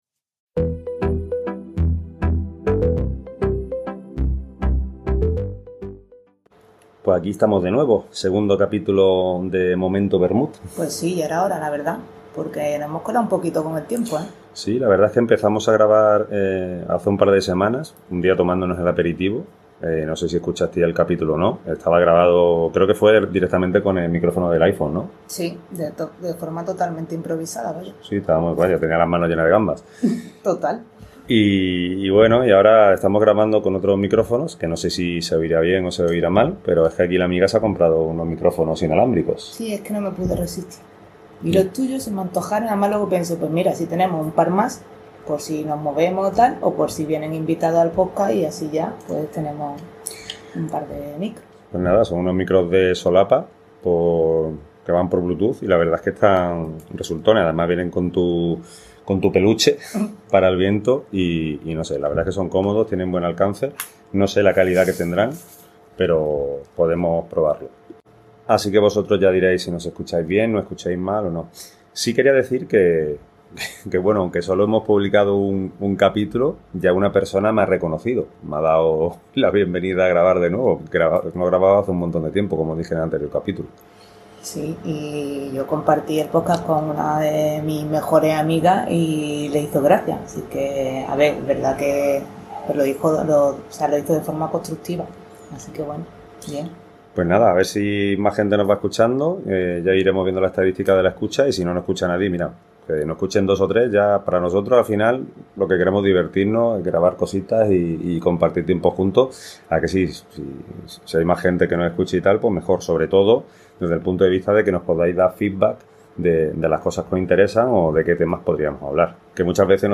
Ya está aquí el episodio de Momento Vermú, ese ratito de charla en el que todo puede pasar.
Un episodio ligero, divertido y con ese toque improvisado que hace especial nuestro Momento Vermú.